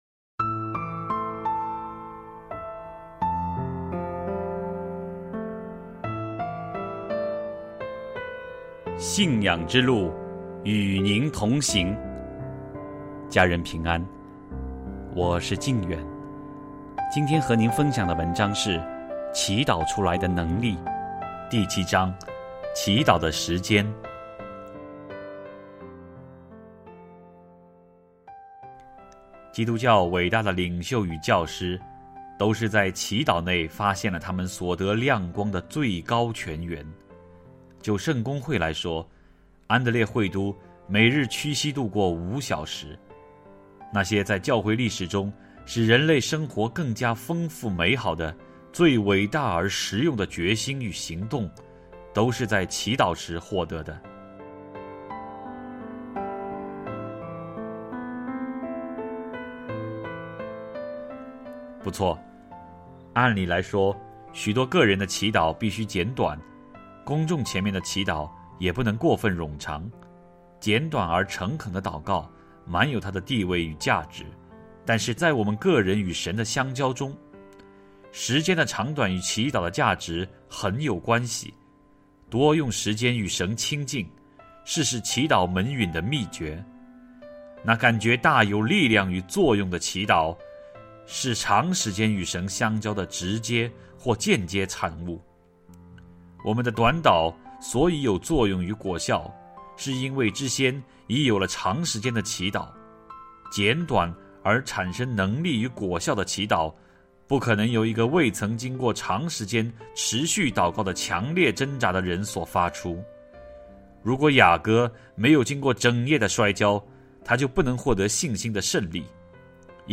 首页 > 有声书 | 灵性生活 | 祈祷出来的能力 > 祈祷出来的能力 第七章：祈祷的时间